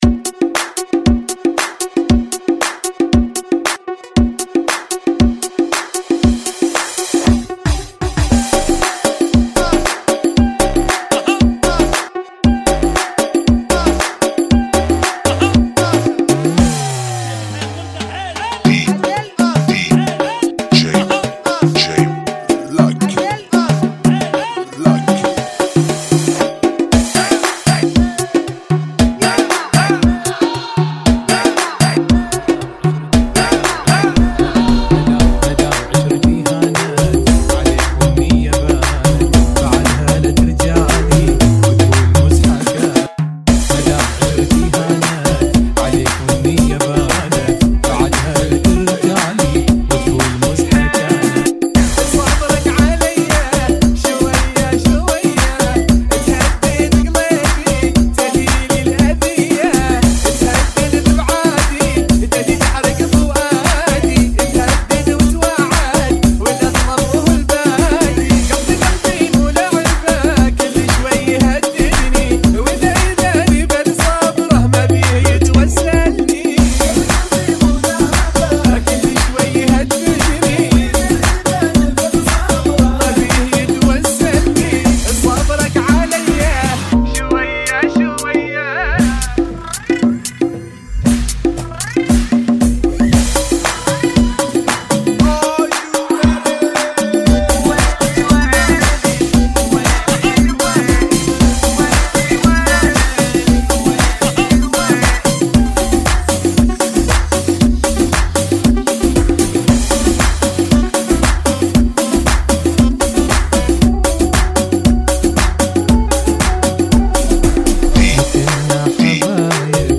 116 Bpm